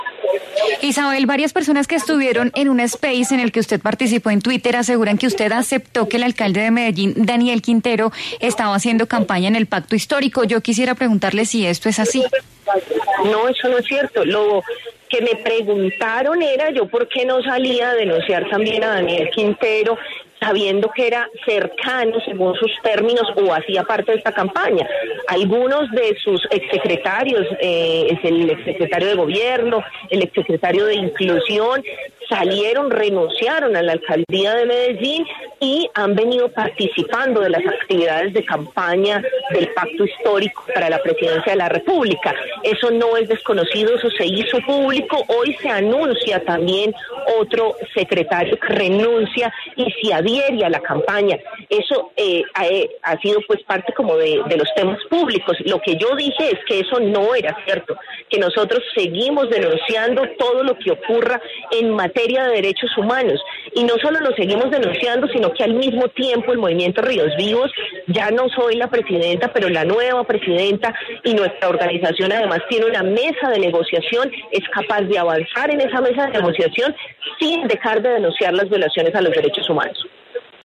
En diálogo con La W, la congresista electa por el Pacto Histórico Isabel Zuleta habló sobre la polémica que sostuvo con el candidato presidencial Sergio Fajardo luego de haber dicho que ya lo habían ‘quemado’ en su carrera política, pero también aclaró el rumor acerca de si fue cierto que ella dijo que el alcalde de Medellín, Daniel Quintero, estaba haciendo política para el Pacto Histórico.